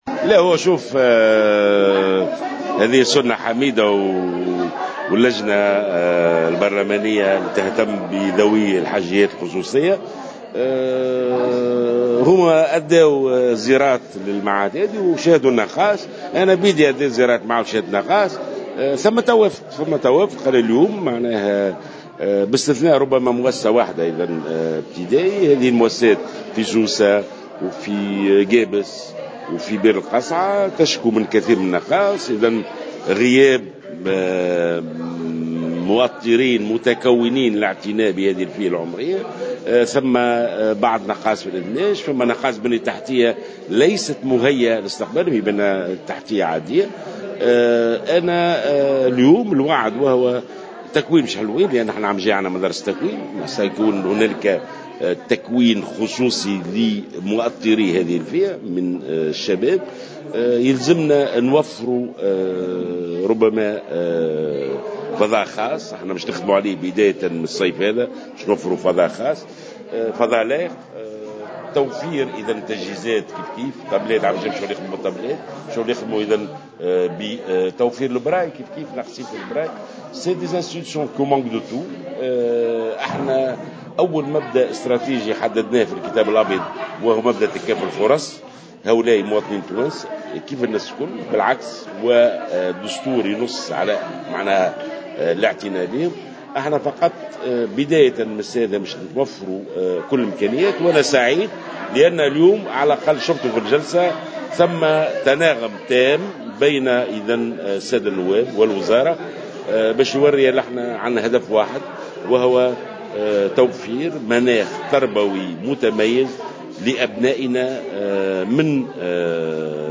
وقال جلول في تصريح إعلامي على هامش الاستماع له من قبل لجنة شؤون ذوي الإعاقة والفئات الهشة بمجلس نواب الشعب، إن من بين الإجراءات تخصيص فضاءات خاصة بهذه الفئات مع تكوين خصوصي للمؤطرين و توفير وسائل تقنية خاصة بكل إعاقة على غرار اللوحات الرقمية و تقنيات "البراي".